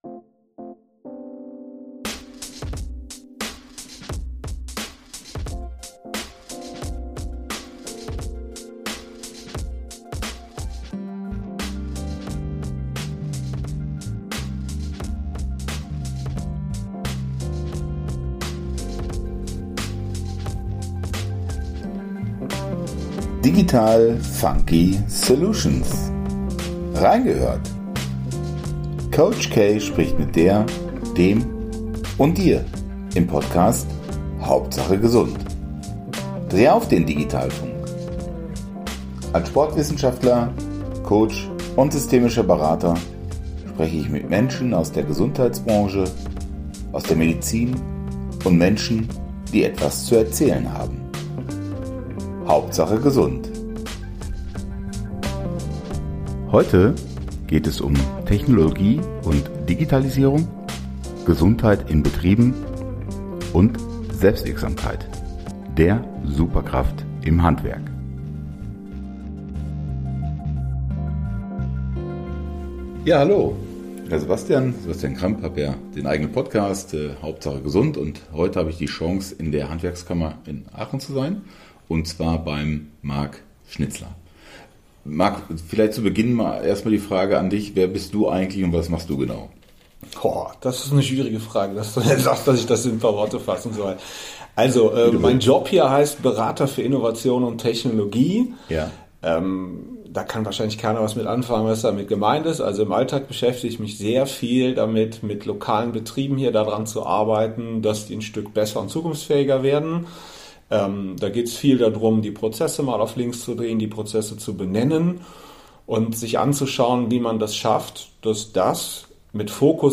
Wie kann man diesen identitätsstiftenden Wert des Handwerks bewahren, zukunftsfähig wirtschaften und gleichzeitig Prozesse digitalisieren? Es entwickelte sich ein spannendes Gespräch...